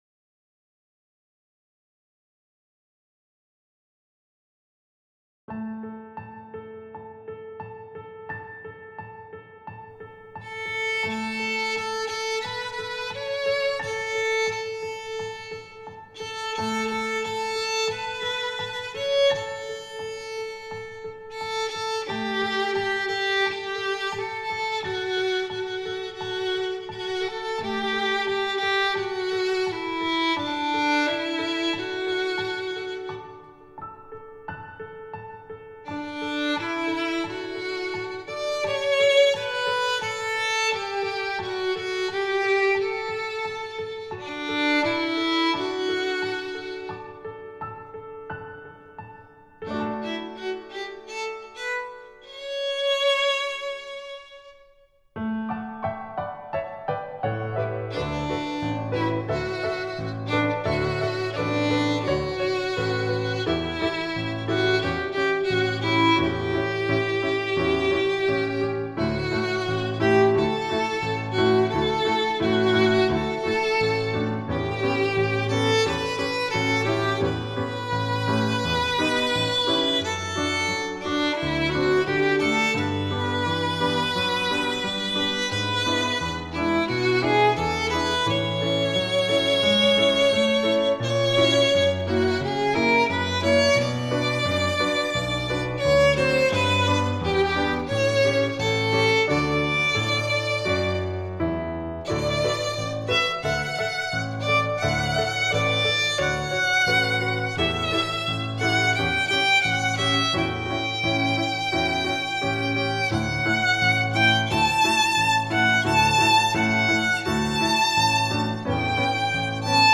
Instrumentation: Violin with Piano Accompaniment